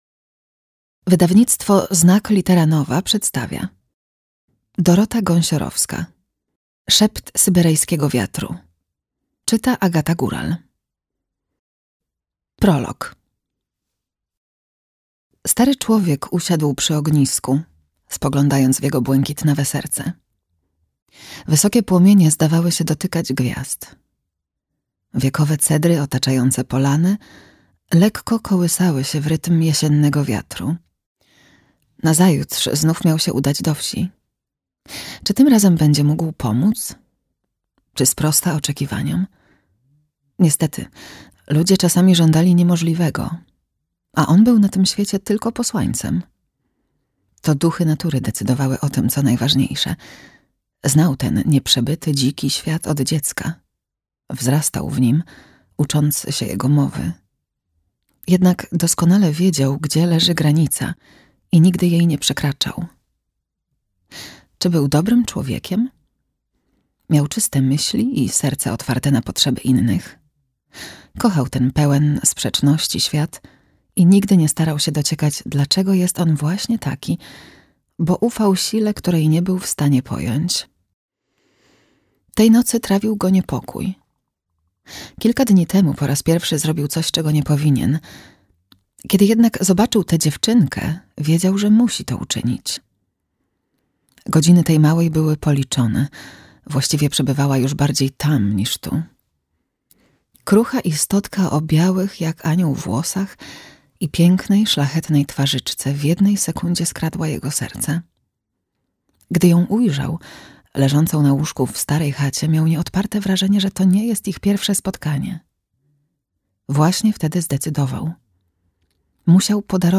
Szept syberyjskiego wiatru - Dorota Gąsiorowska - audiobook + książka - Legimi online